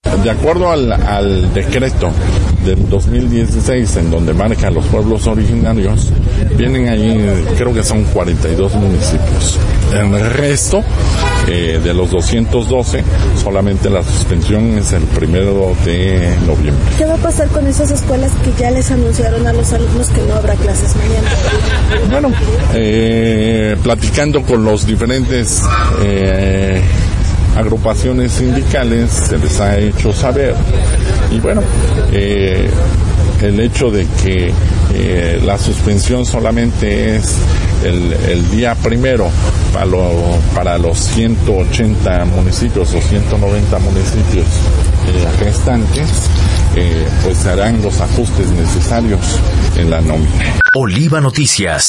En entrevista, recordó que solamente los 42 Pueblos Originarios de la Entidad, tienen permitido suspender clases del 31 de octubre al 03 de noviembre; el resto de municipios, solo los días 01 y 02.